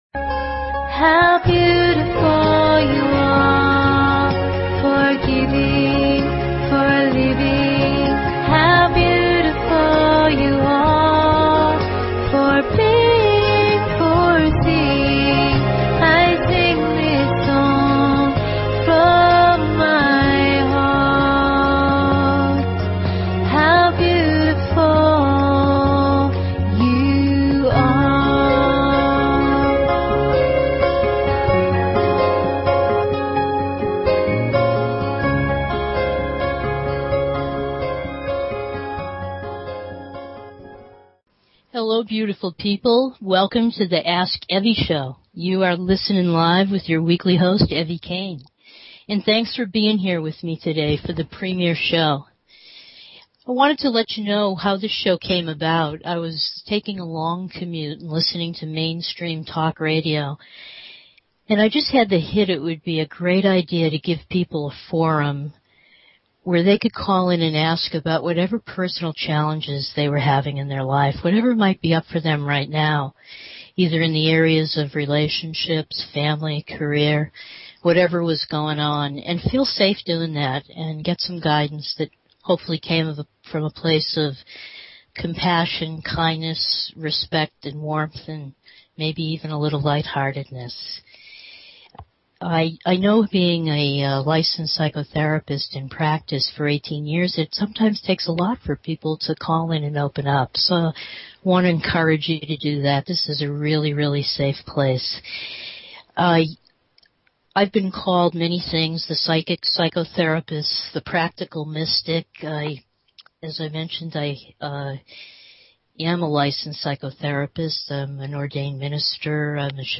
Talk Show Episode, Audio Podcast
Courtesy of BBS Radio